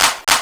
Purps Clap (3).wav